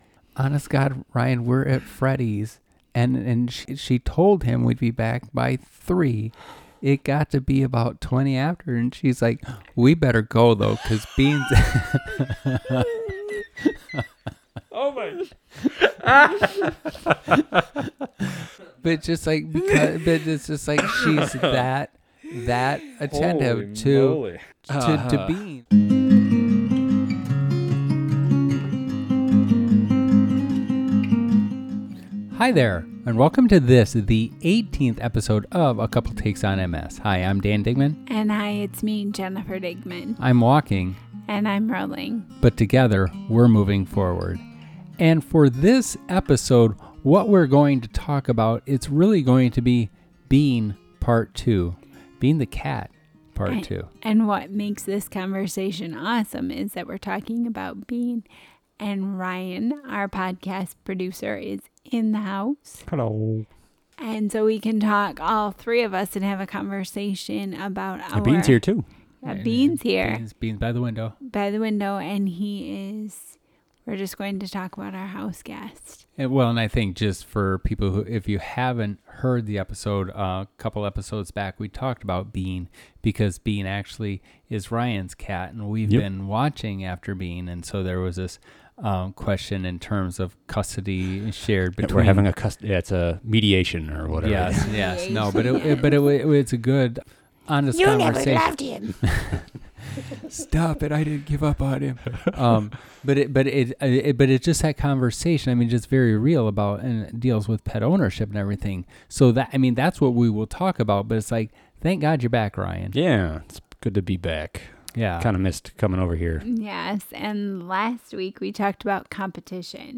a chit-chat episode that mainly focused on Bean and what the future may hold for the fuzzy fellow.